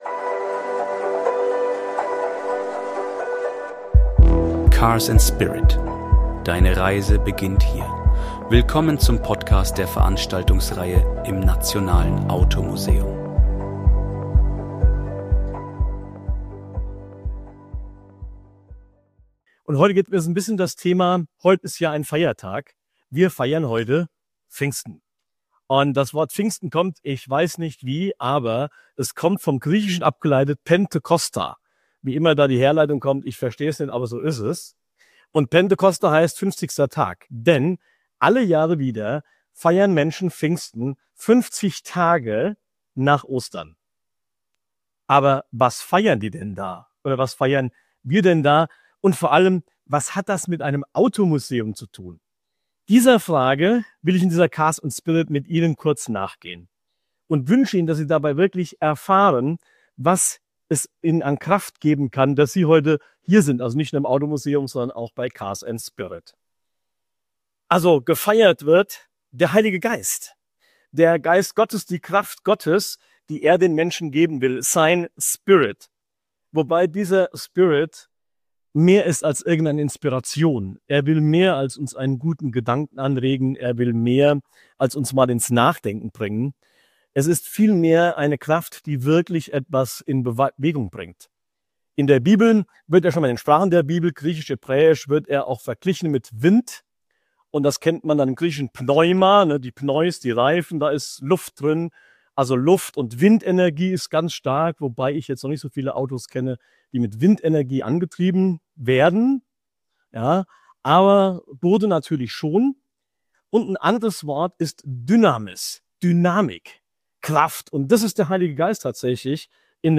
Die Veranstaltungsreihe im Nationalen Automuseum.